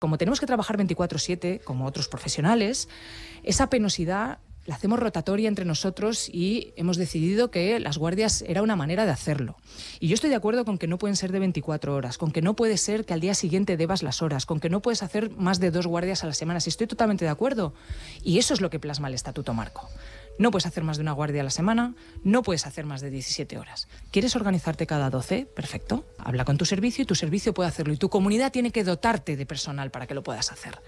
En una entrevista a Catalunya Ràdio, Garcia assegura que precisament, per primera vegada, es limiten les guàrdies: de 24 hores que hi ha fins ara es passa a un màxim de 17 hores, i com a màxim, se’n podran fer cinc mensuals.